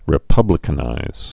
(rĭ-pŭblĭ-kə-nīz)